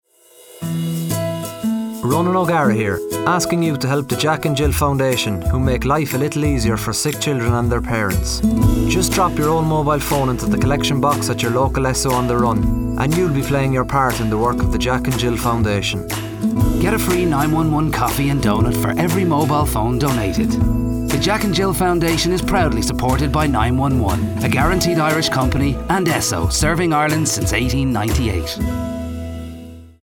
To listen to the Radio Ad Click here 911 ESSO – JACK & JILL